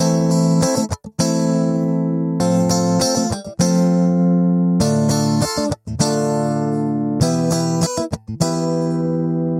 四和弦吉他Ac 1
描述：电吉他
Tag: 100 bpm Pop Loops Guitar Acoustic Loops 826.98 KB wav Key : A